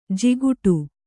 ♪ jiguṭu